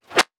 weapon_bullet_flyby_17.wav